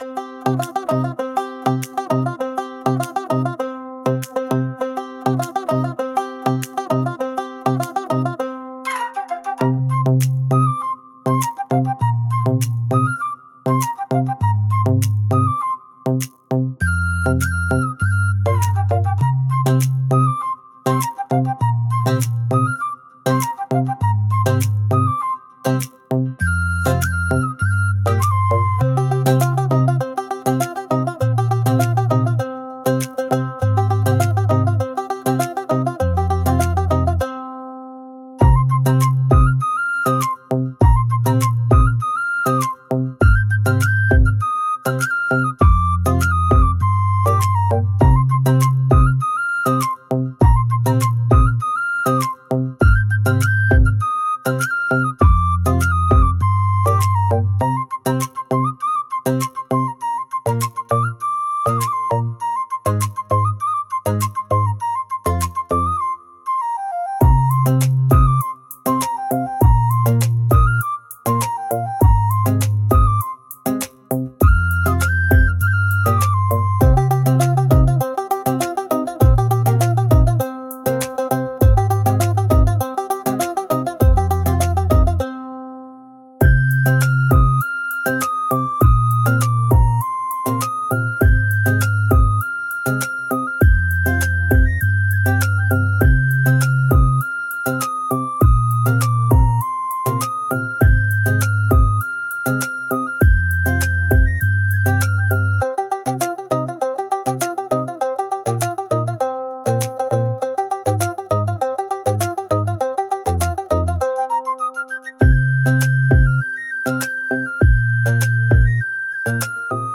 C Minor – 100 BPM
Bollywood
Pop